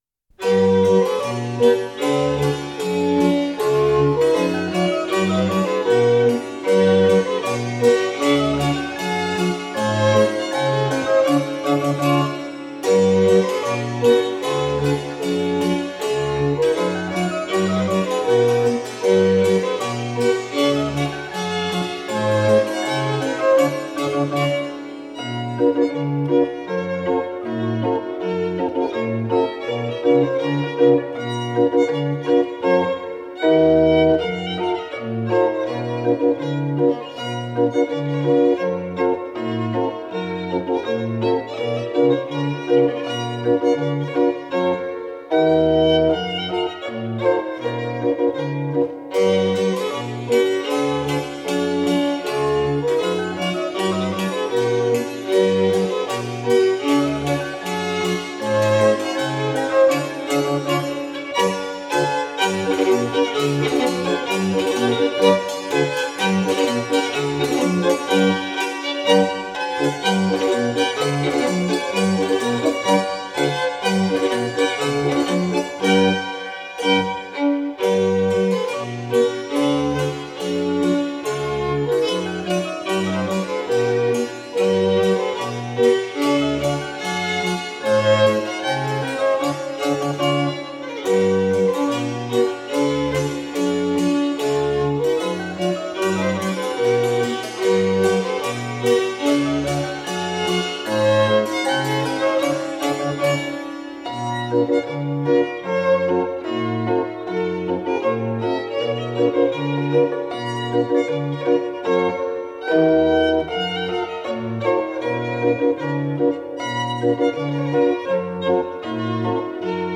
using the style of traditional Emmental dance melodies